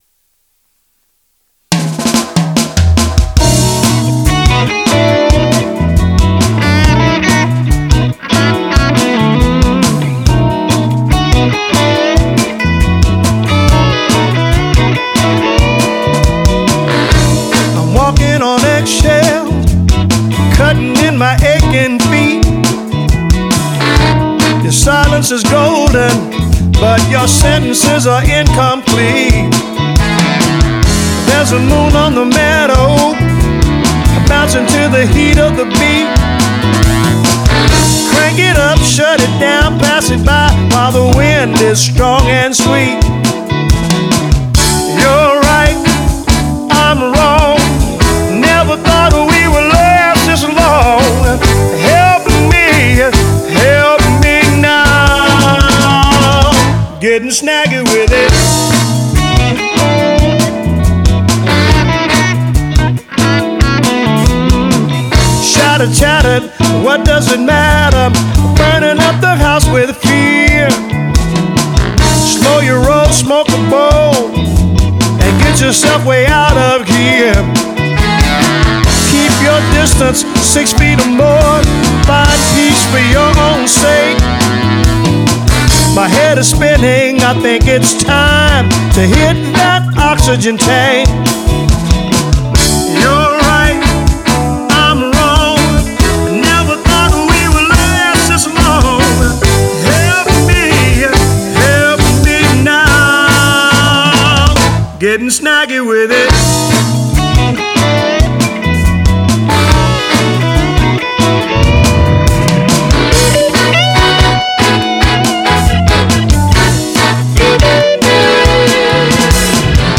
Rythm and lead Guitars